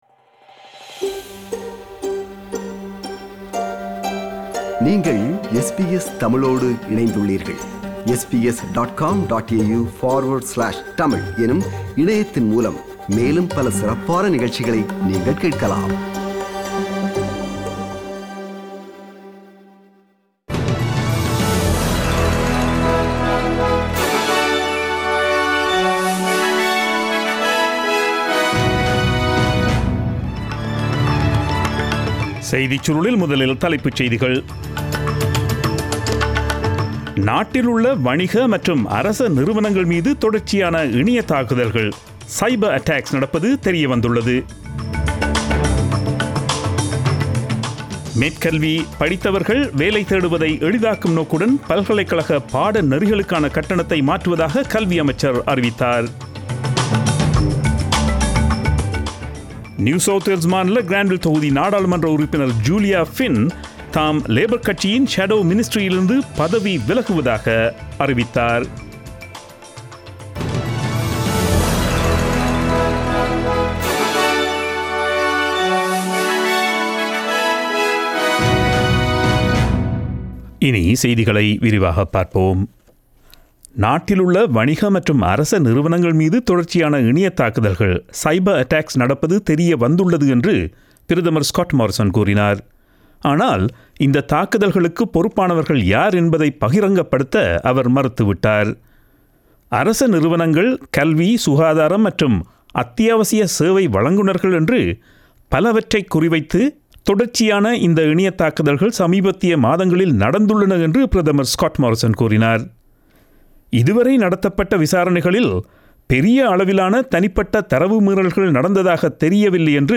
Australian news bulletin aired on Friday 19 June 2020 at 8pm.